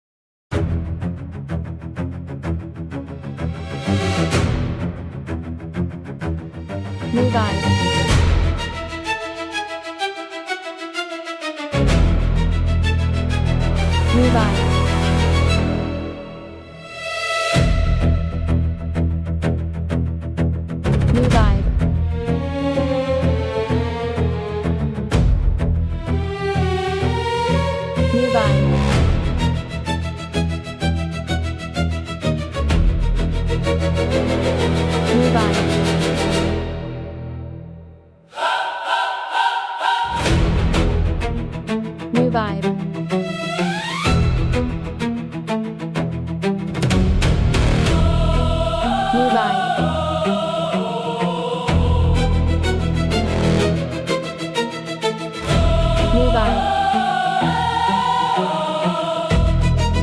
Genre: Cinematic